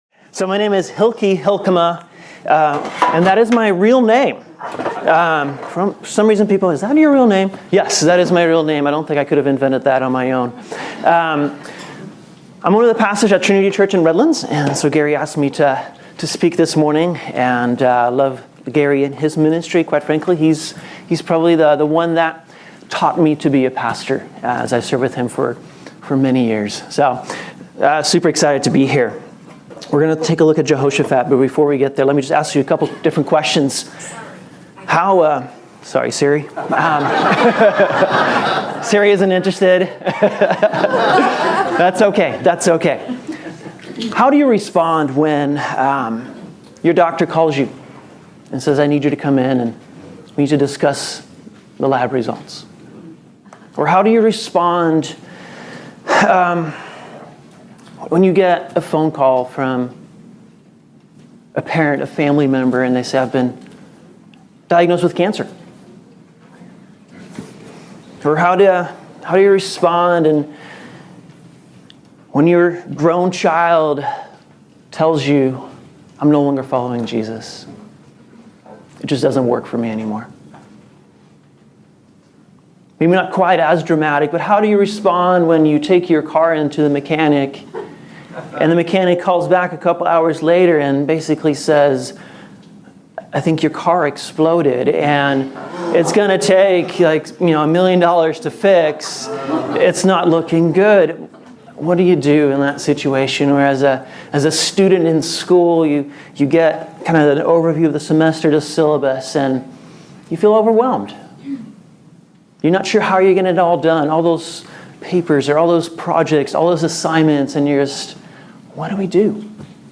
Download Audio Facebook Tweet Link Share Link Send Email More Messages Associated With " Standalone Sermon "...